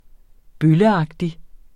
Udtale [ ˈbøləˌɑgdi ]